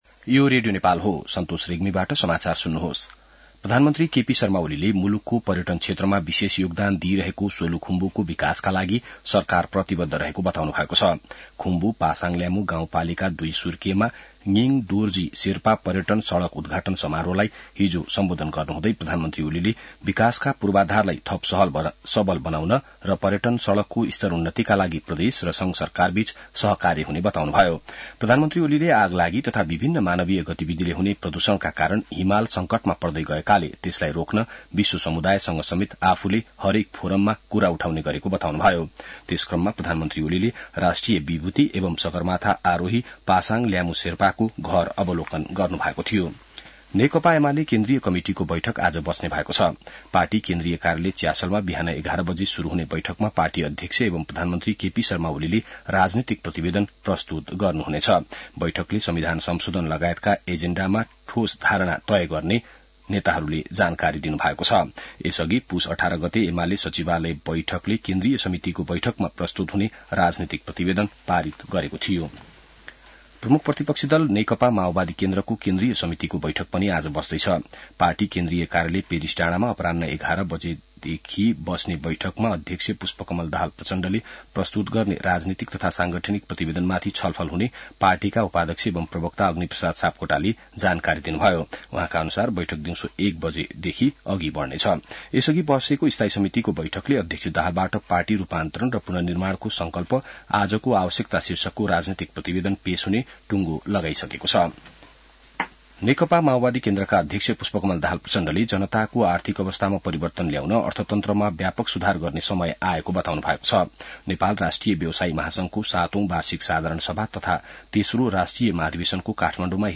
बिहान ६ बजेको नेपाली समाचार : २२ पुष , २०८१